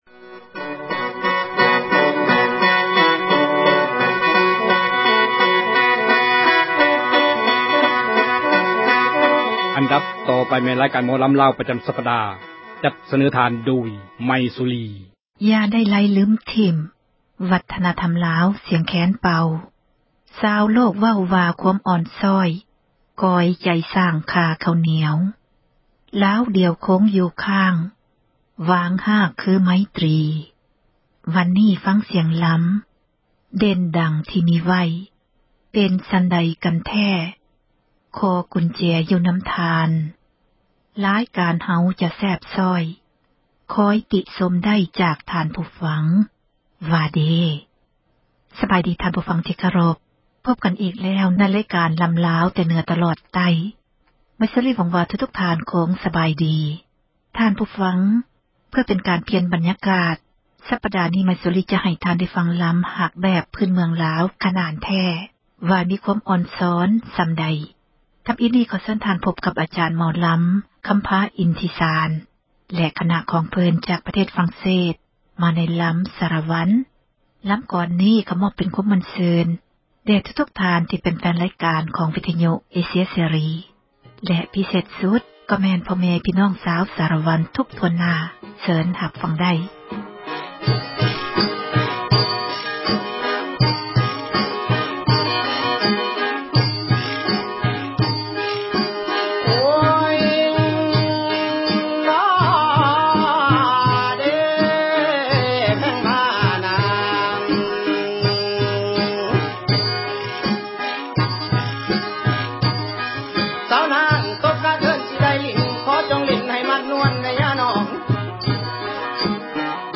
ໝໍລໍາ